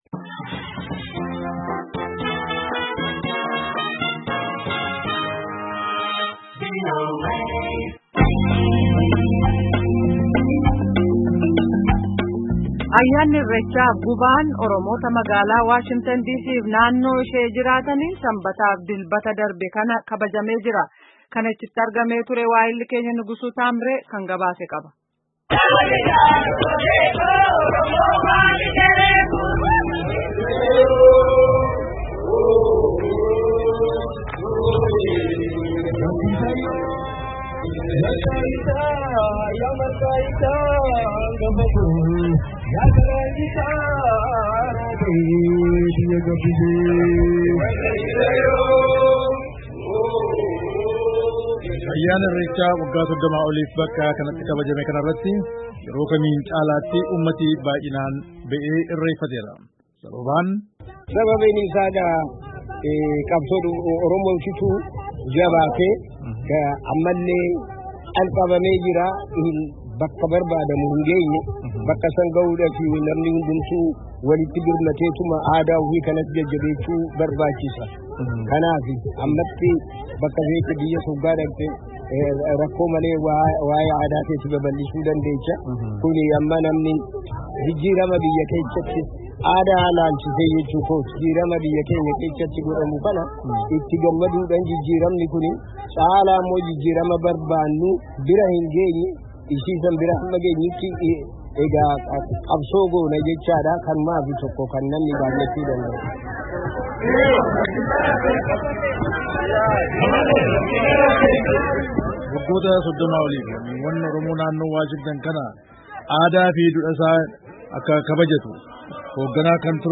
WAASHINGITAN, DIISII —